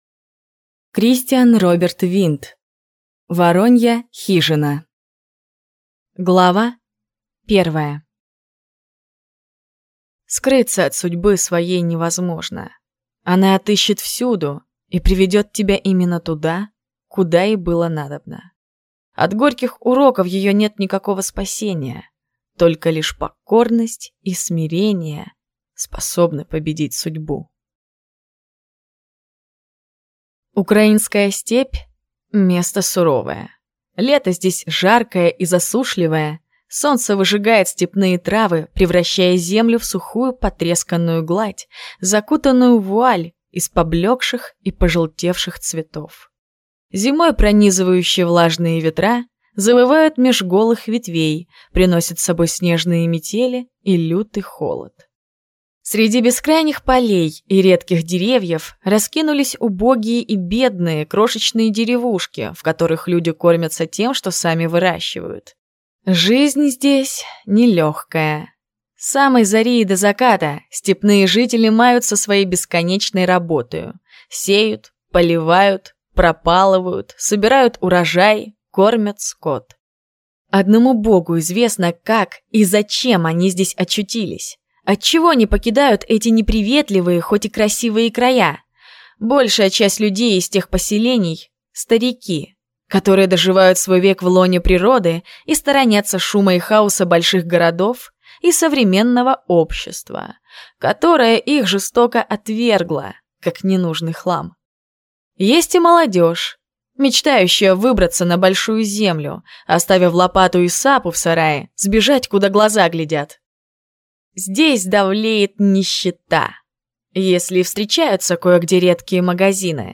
Аудиокнига Воронья хижина | Библиотека аудиокниг